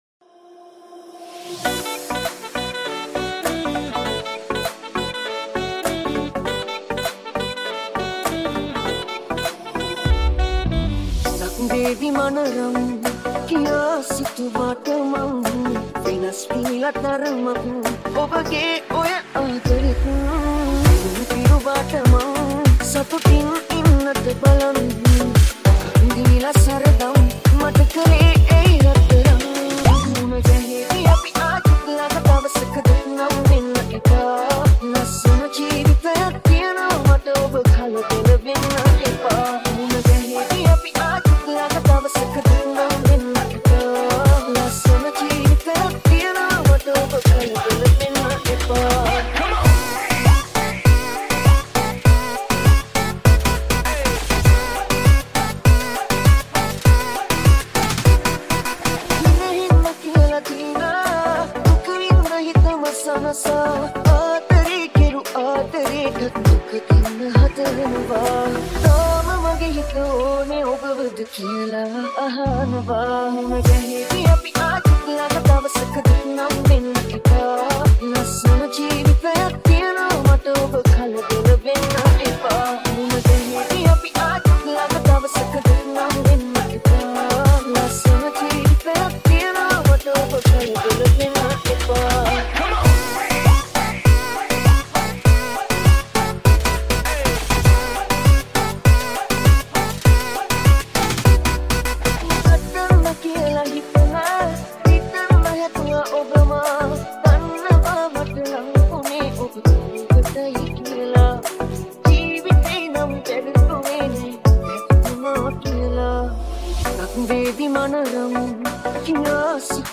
Sinhala Remix Song